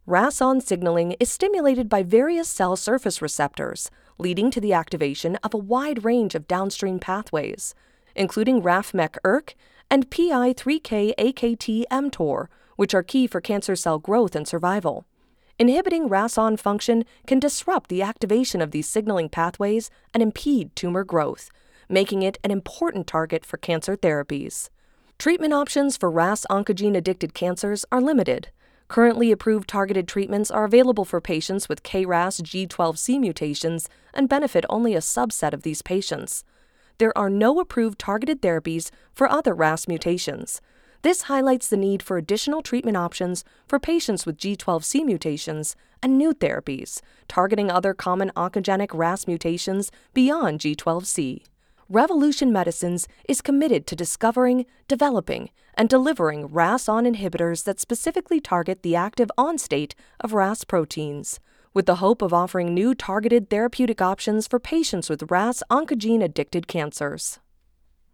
Female
Yng Adult (18-29), Adult (30-50)
Medical Narrations
Words that describe my voice are Warm, Friendly, Conversational.